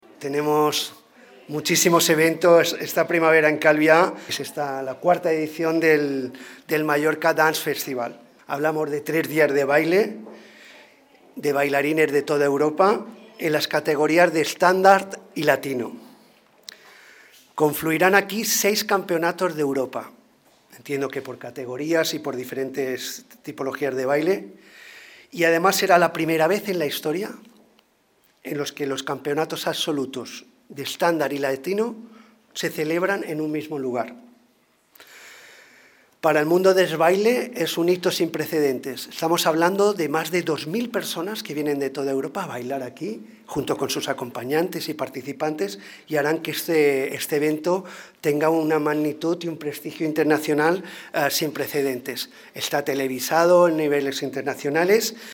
declaraciones-alcalde-juan-antonio-amengual.mp3